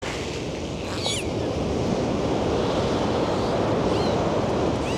delfiny
delfiny.mp3